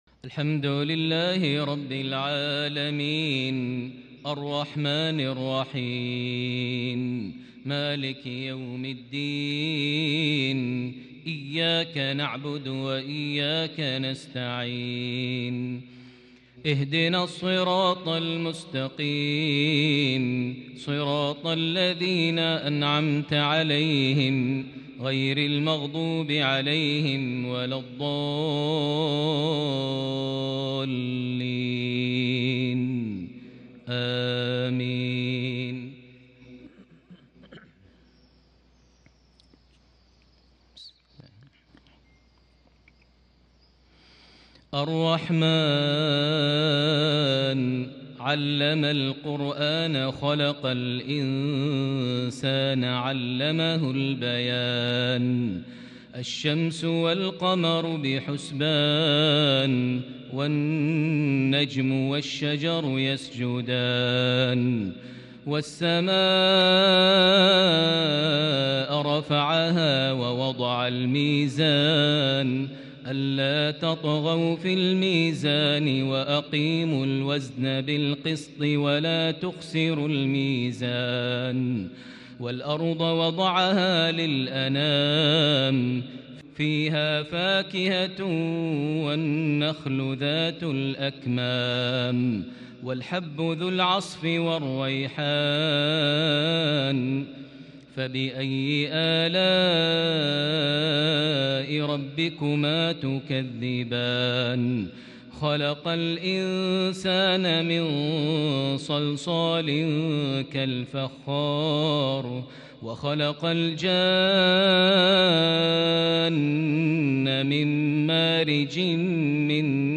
صلاة الفجر ٢٤ شعبان ١٤٤١هـ سورة الرحمن ١-٤٧ > 1441 هـ > الفروض - تلاوات ماهر المعيقلي